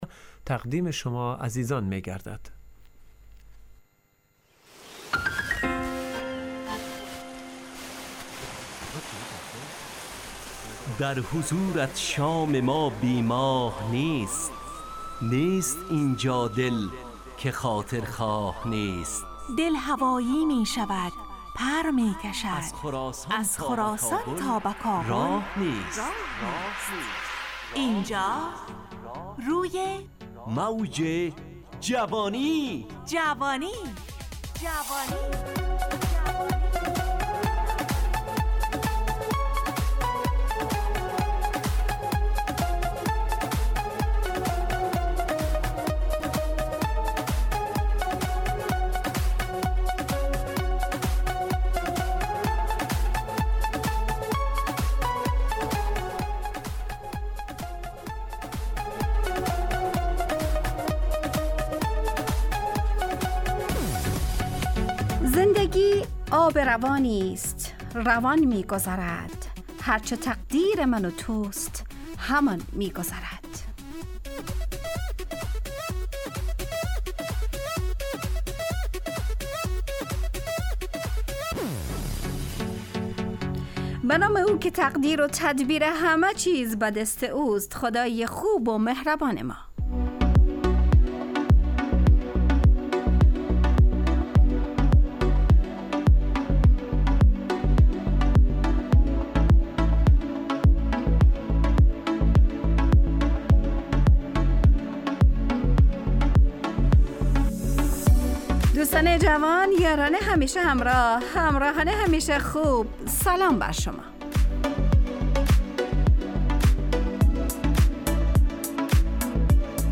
روی موج جوانی 1403/3/5/برنامه شادو عصرانه رادیودری.
همراه با ترانه و موسیقی مدت برنامه 70 دقیقه . بحث محوری این هفته (امانت)